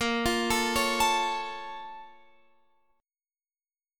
A#mM7 Chord